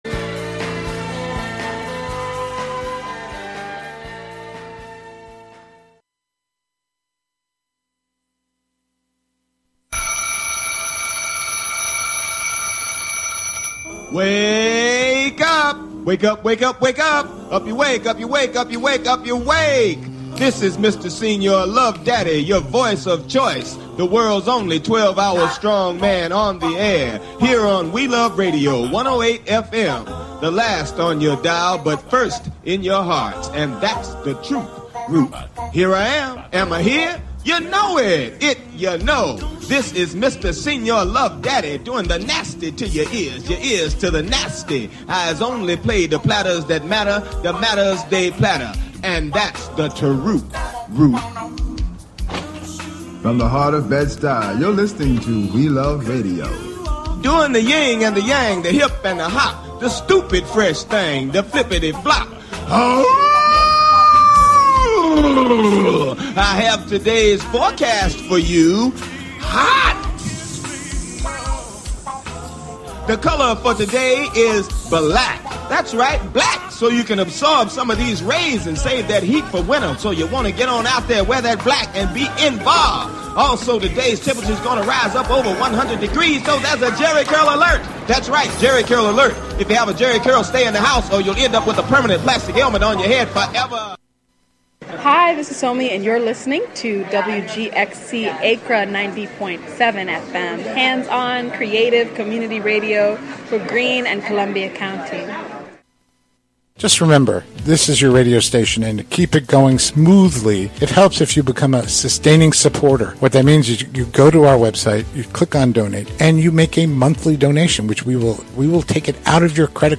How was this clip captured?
It’s about breaking barriers, making bold moves, and exploring the crossroads of business, politics, and identity. During our roundtable, we’ll dive into Vice President Harris’ historic candidacy and discuss how voting with your wallet could shape the future for small business owners.